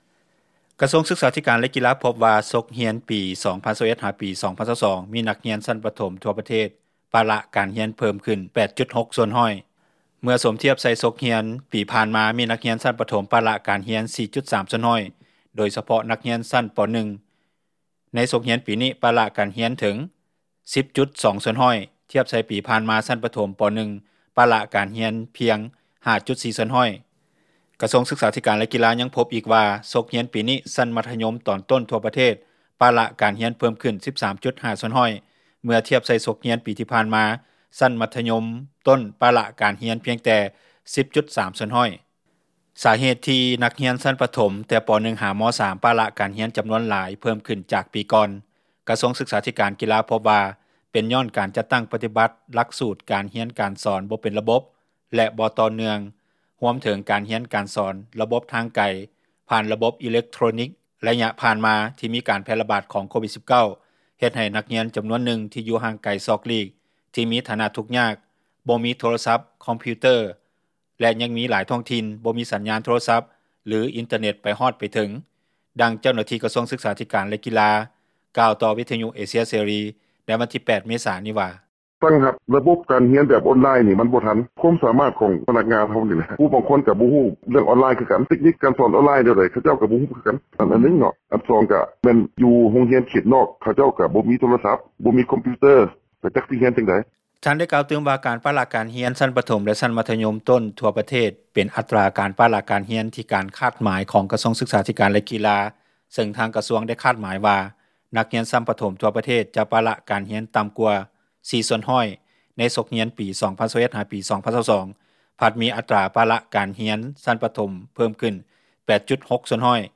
ດັ່ງເຈົ້າໜ້າທີ່ ກະຊວງສຶຶກສາທິການ ແລະກິລາ ກ່າວຕໍ່ວິທຍຸເອເຊັຽເສຣີ ໃນວັນທີ 8 ເມສານີ້ວ່າ: